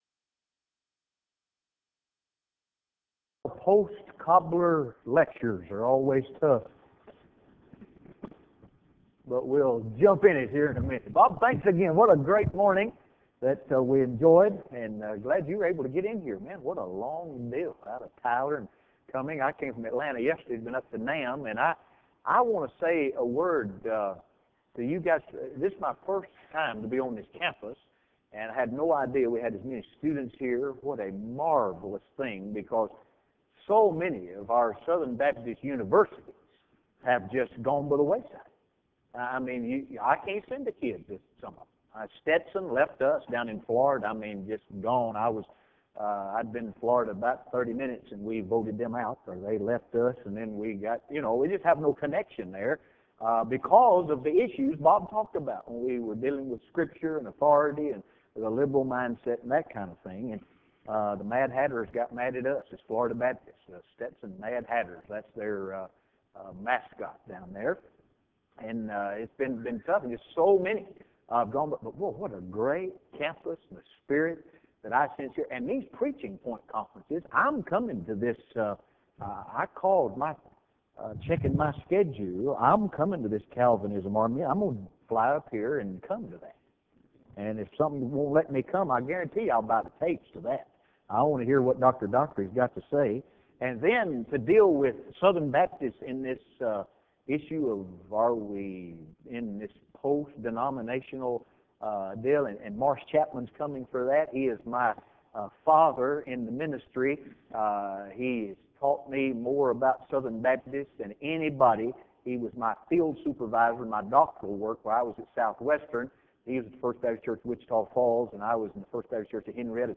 Address: "The Science of Preaching"